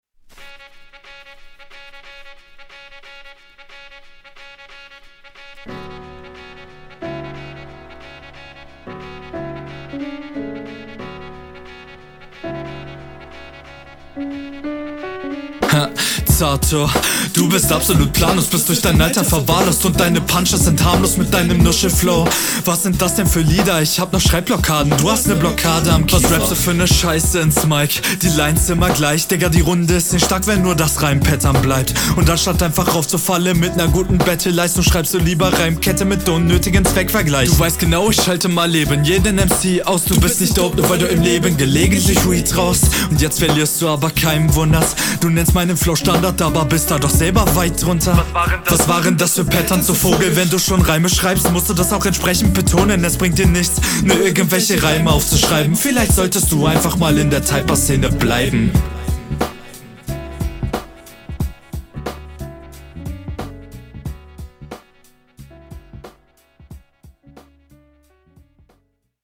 Bitte synchrone und bessere Doubles!!!.
Bisschen bei den Shuffles aufpassen, dass du dich nicht verhaspelst